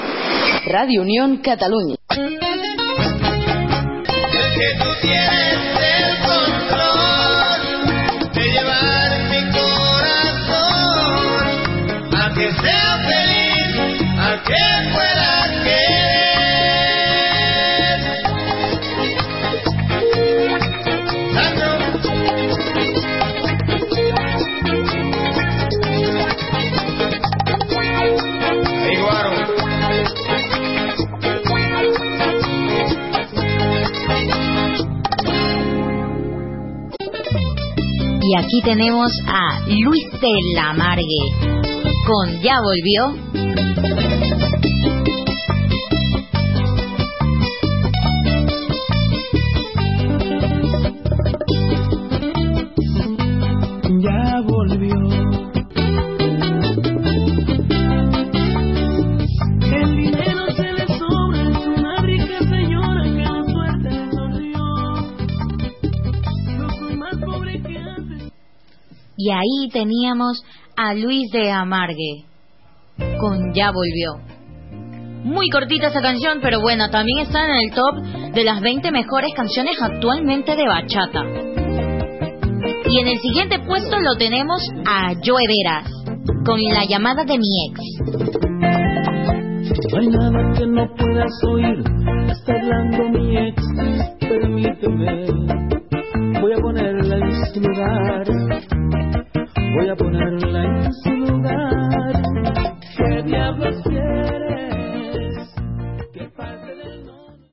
Indicatiu de la ràdio, tema musical i presentació d'un altre
Entreteniment